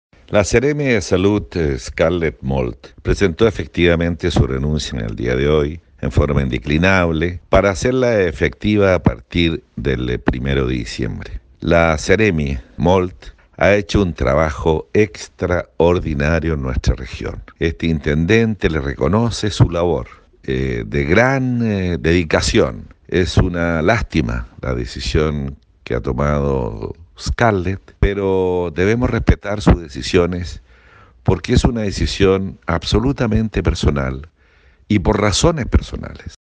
La decisión fue por motivos personales según se informó desde la misma secretaría regional ministerial, lo que confirmó el intendente de Los Lagos, Harry Jurgensen.